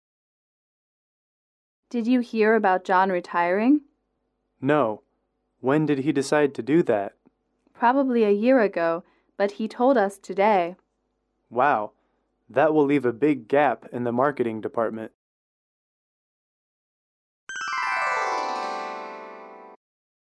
英语口语情景短对话07-2：约翰退休了